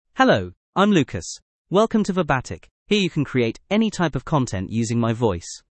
MaleEnglish (United Kingdom)
LucasMale English AI voice
Voice sample
Lucas delivers clear pronunciation with authentic United Kingdom English intonation, making your content sound professionally produced.